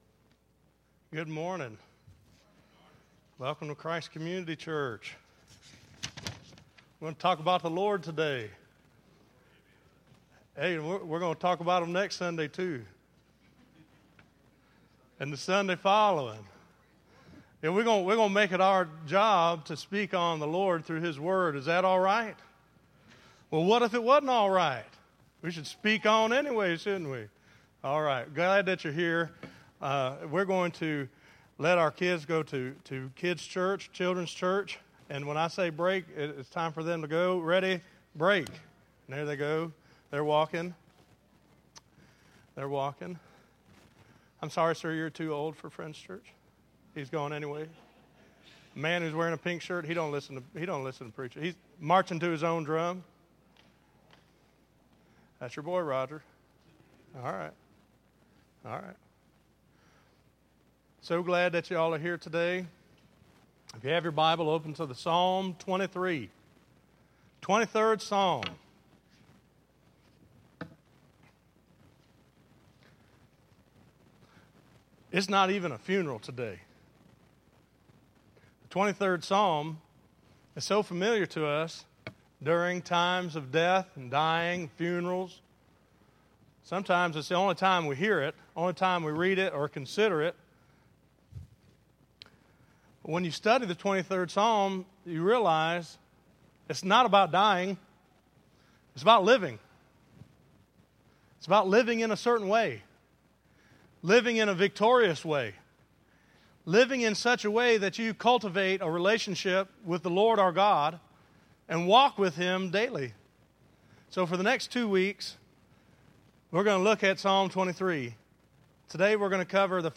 Listen to The Shepherd is My Lord Part 1 - 03_16_2914_Sermon.mp3